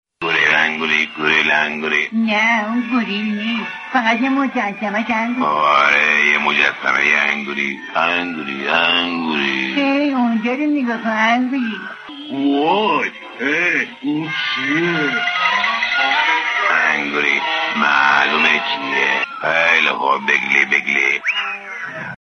بخشی از صداپیشگی صادق ماهرو در نقش «داروغه ناتینگهام» در انیمیشن «رابین هود»